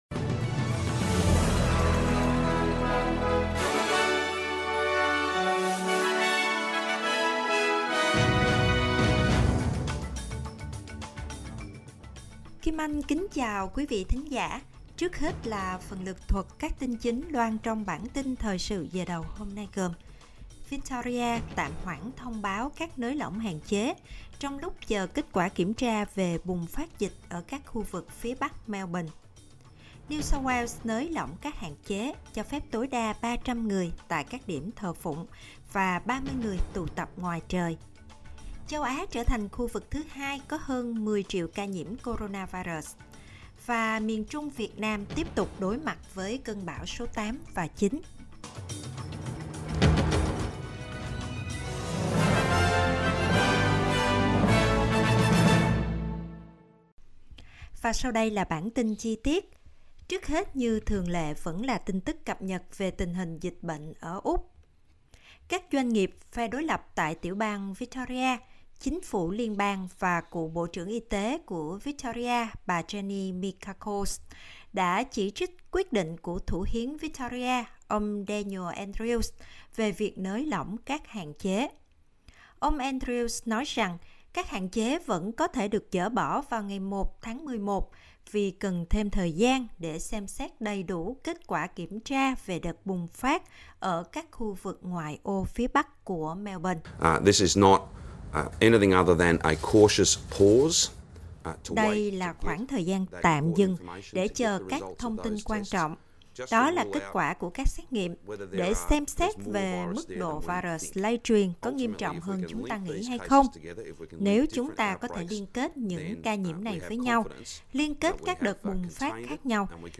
Vietnamese news bulletin Source: AAP